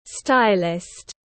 Nhà tạo mẫu tiếng anh gọi là stylist, phiên âm tiếng anh đọc là /ˈstaɪlɪst/.
Stylist /ˈstaɪlɪst/